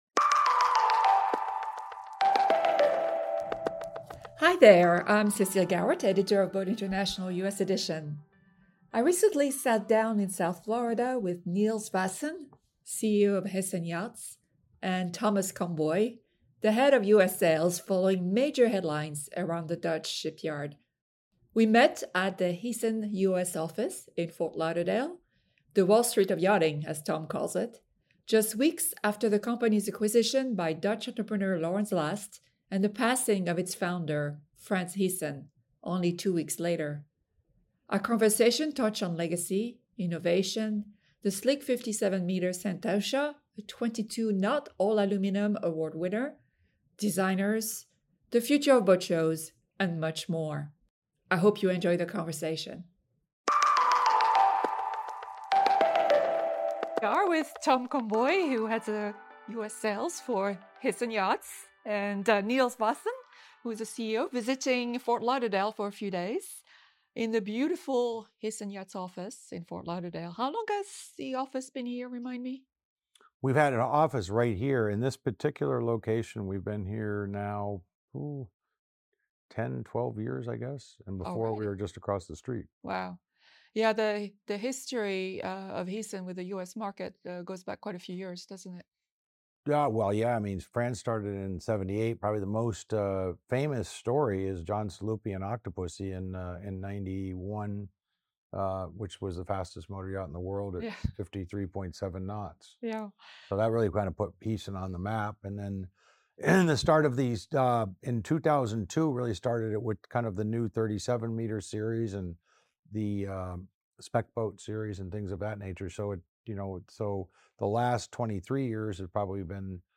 Beyond the Horizon: A Conversation with Heesen Yachts